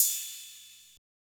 Cymbal.wav